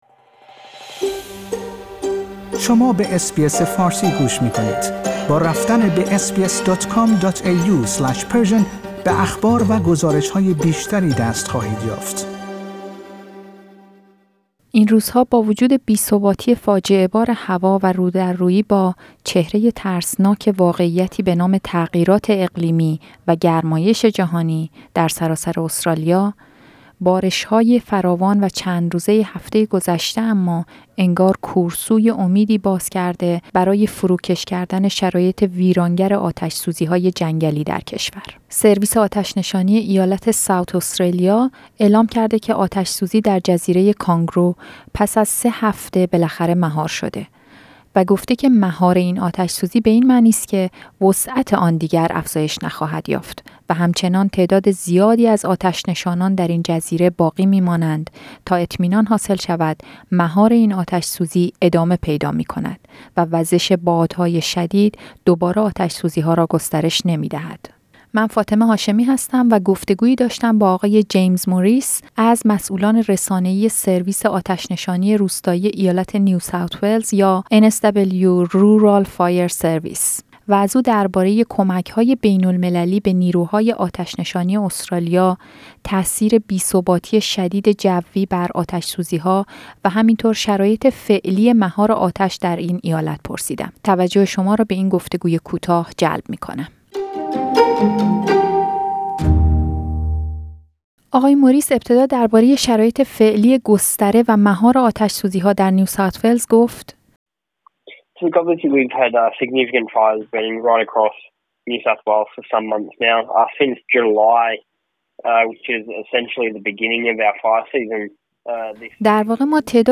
در گفتگویی